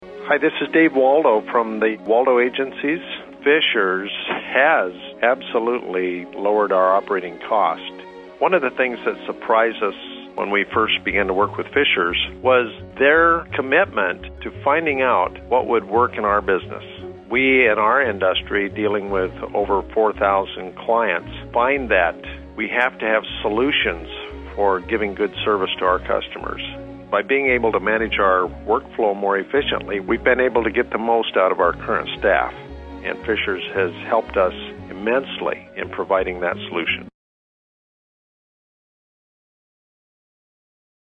Audio Testimonials | Fisher's Technology
Audio Testimonials Each audio testimonial features a Fisher's customer talking about why they choose to do business with Fisher's Technology. These short segments were taken from a recent Fisher's radio ad series.